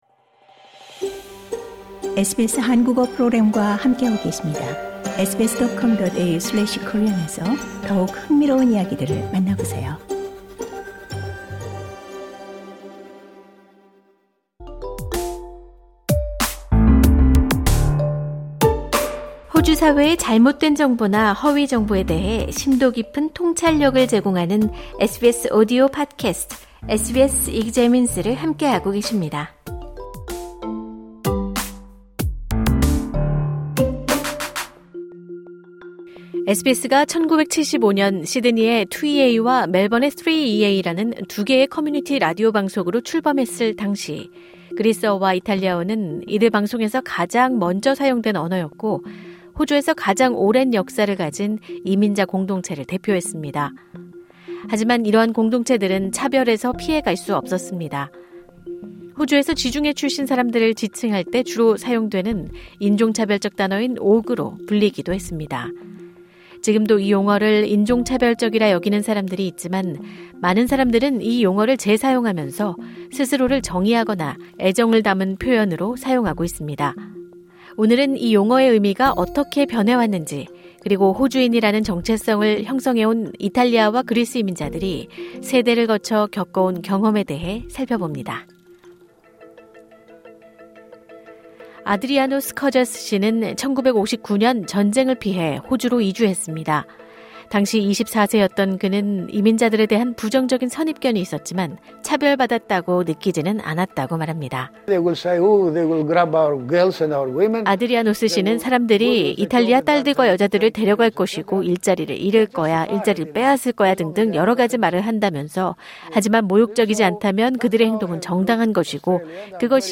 These Greek and Italian migrants share their experiences of Australia and their place within it.